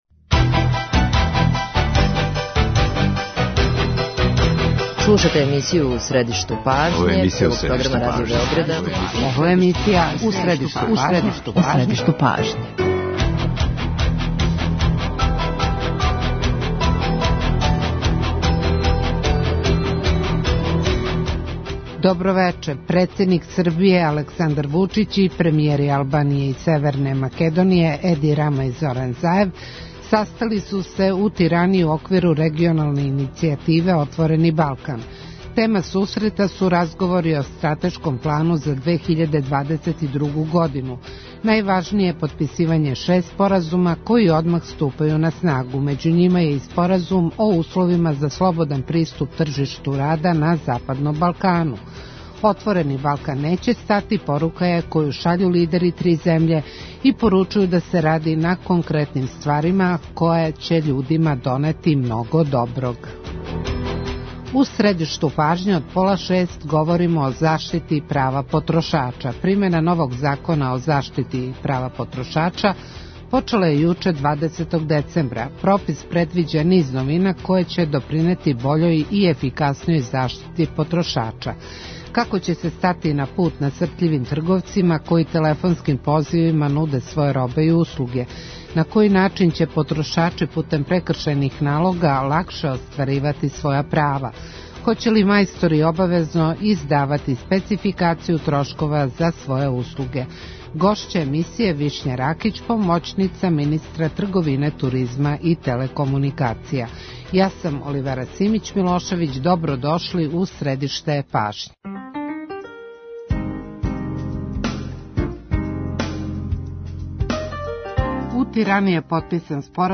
Гошћа емисије је Вишња Ракић, помоћница министра трговине, туризма и телекомуникација.